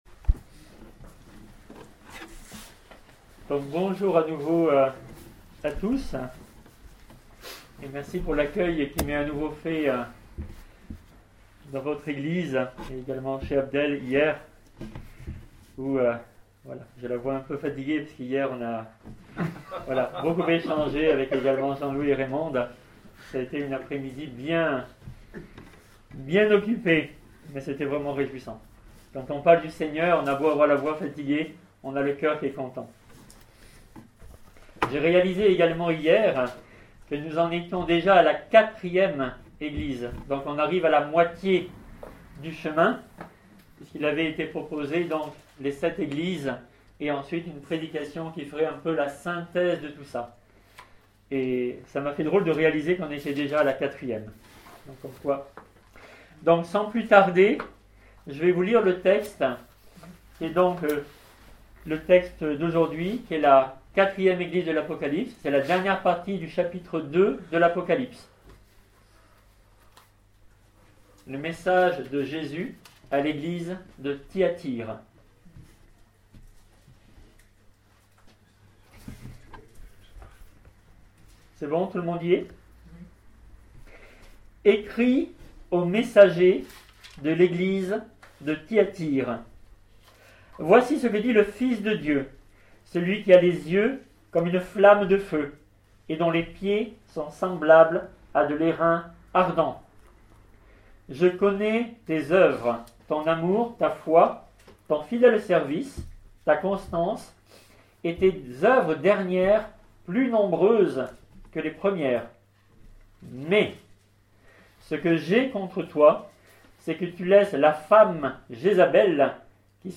Prédicateurs